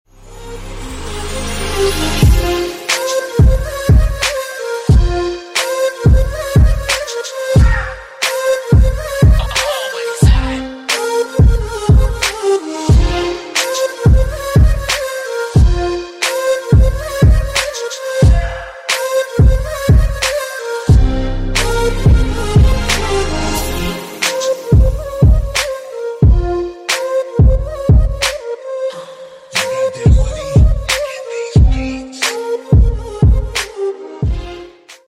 Arabian Instrumental Arabic Flute